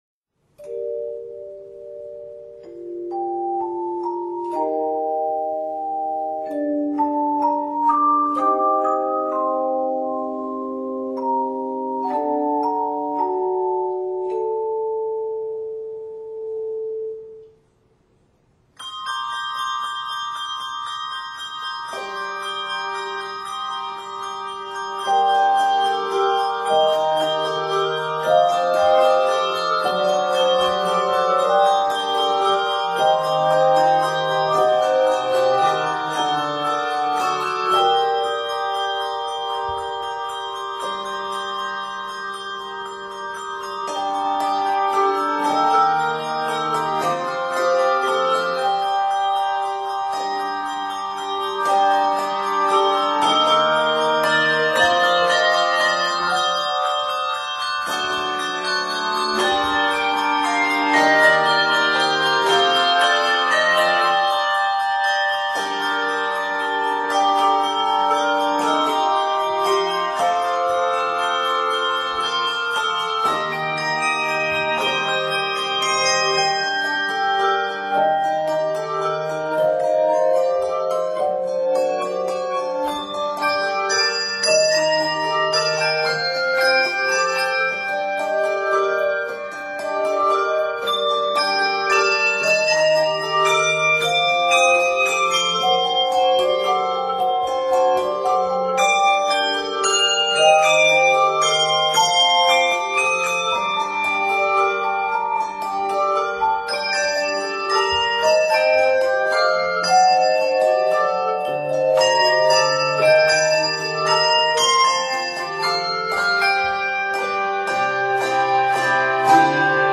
handbell music
Set in g minor, c minor, and f minor, it is 86 measures.